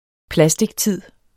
Udtale [ ˈplasdigˌtiðˀ ]